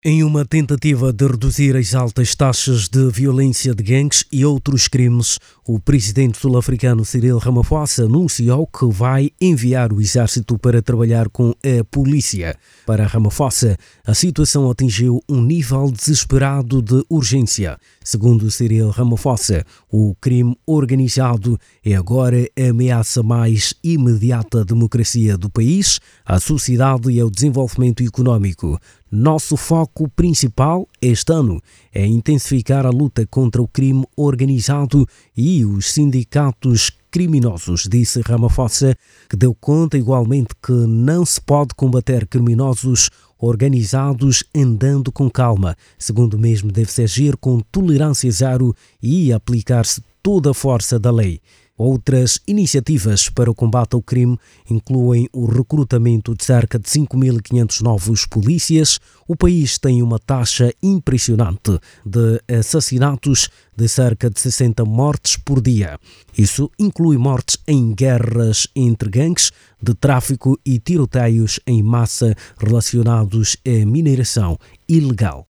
O Presidente sul-africano, Cyril Ramaphosa, anunciou o envio do exército para o combate ao crime organizado. Os números revelam que o país tem uma taxa de assassinatos de cerca de 60 mortes por dia. Ouça o desenvolvimento desta matéria na voz do jornalista